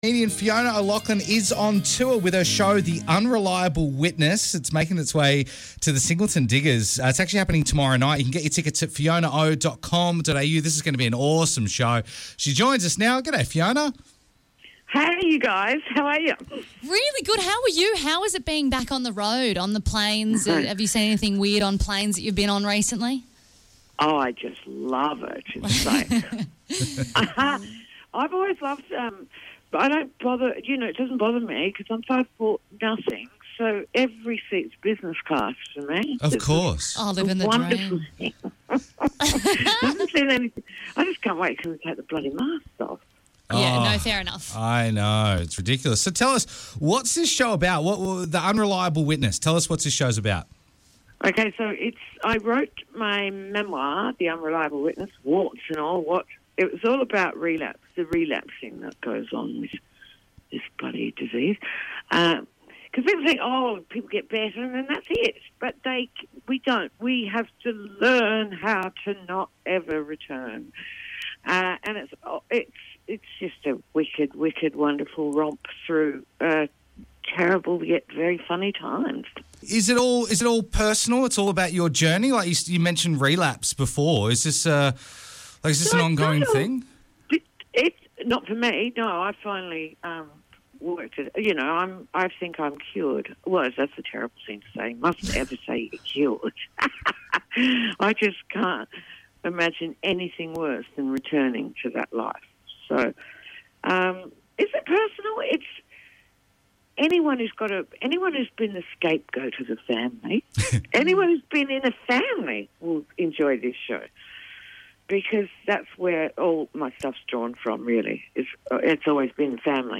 FULL CHAT: Comedian Fiona O'Loughlin 😂